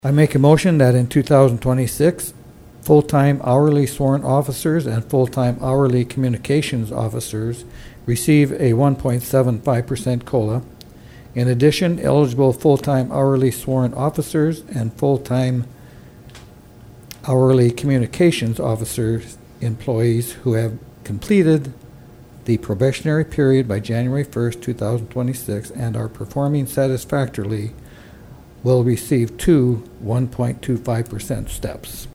Harding continued for police and dispatch communications officers…